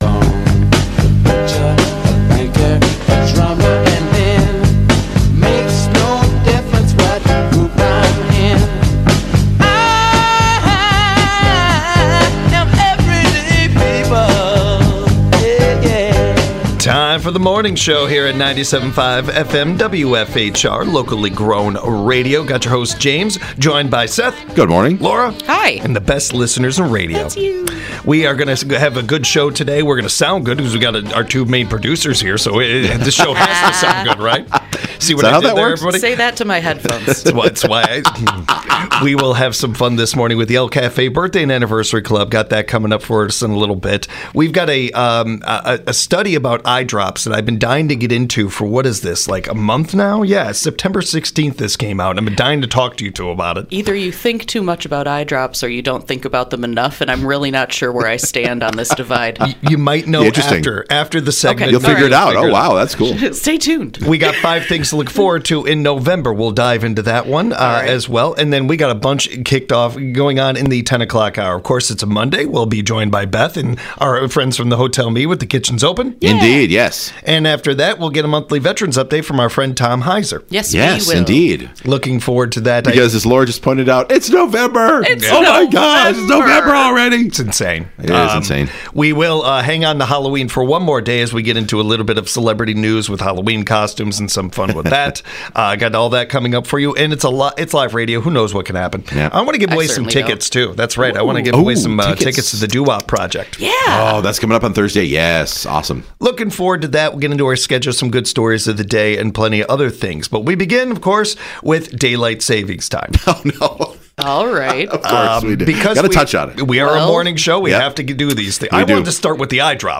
The trio go talk through a list of five things to look forward to in November.